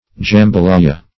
jambalaya \jam`ba*lay"a\ (j[u^]m`b[.a]*l[imac]"[.a]), n.